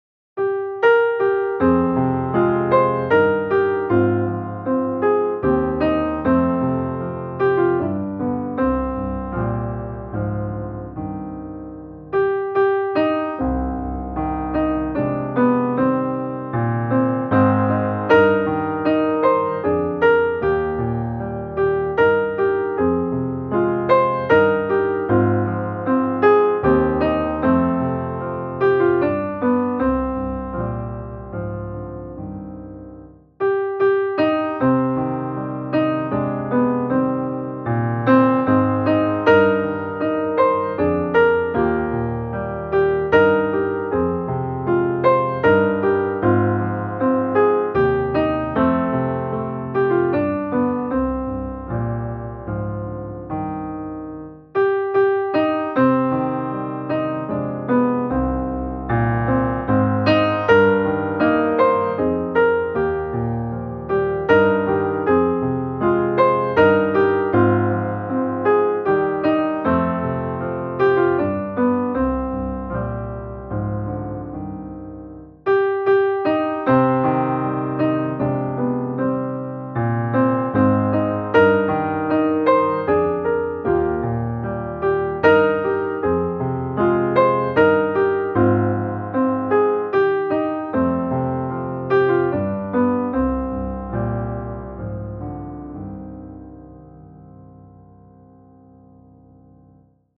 Det finns en väg till himmelen - musikbakgrund